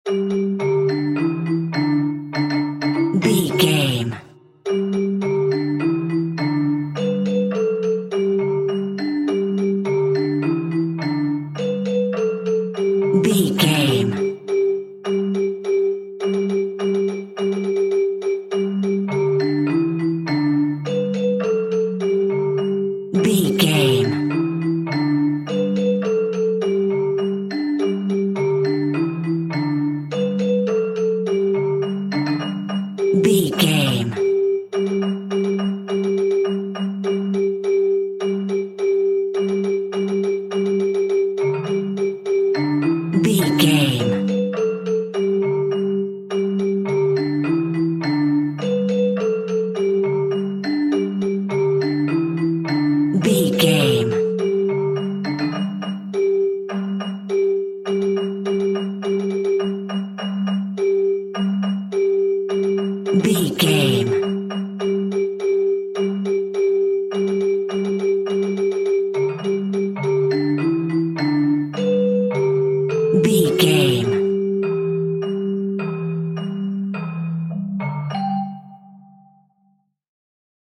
Ionian/Major
nursery rhymes
childrens music